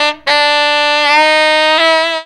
COOL RIFF.wav